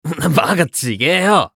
男性
熱血系ボイス～日常ボイス～
【照れながら否定する】